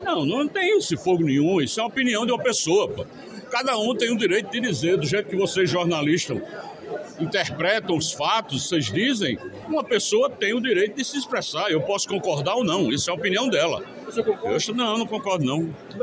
O governador João Azevêdo (PSB) discordou da fala da secretária Desenvolvimento Humano, Pollyana Werton, sobre o prefeito de João Pessoa, Cícero Lucena (Progressistas). A declaração foi feita nesta quarta-feira (7), em entrevista à imprensa.